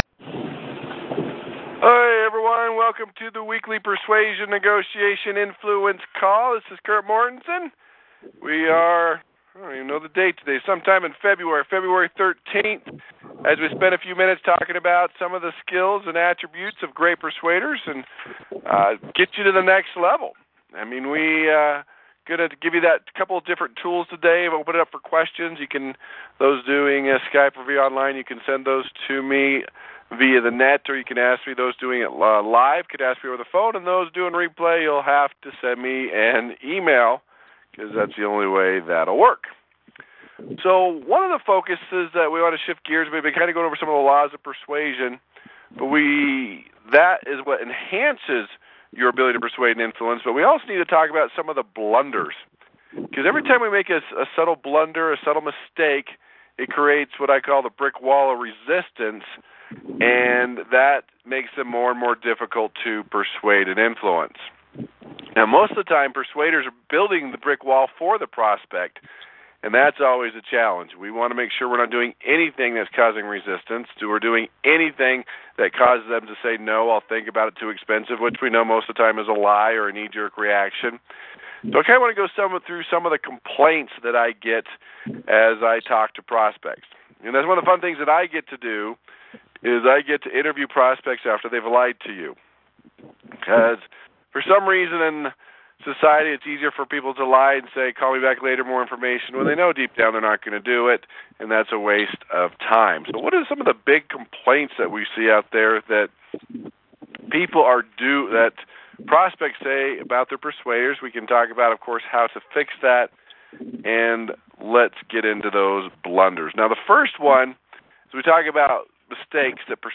‹ Personality Persuasion IQ #1 › Posted in Conference Calls